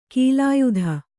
♪ kīlāyudha